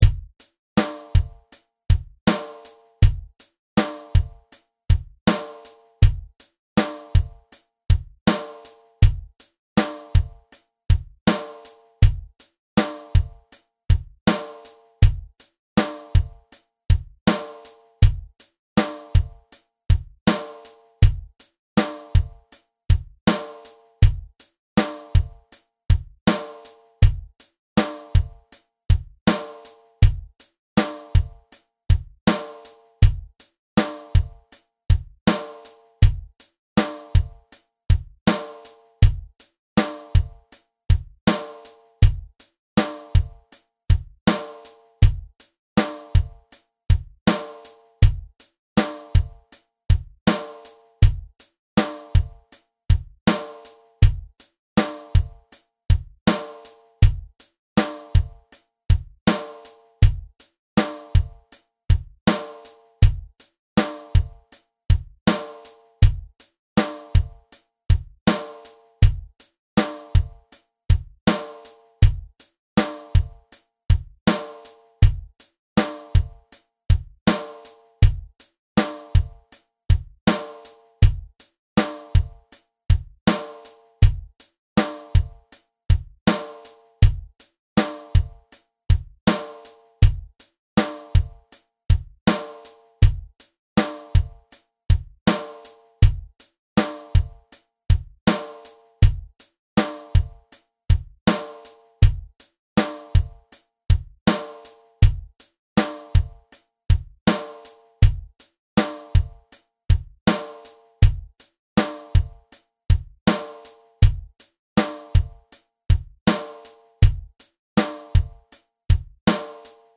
Keyboard ~ 107